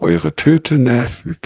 sample02-TensorFlowTTS.wav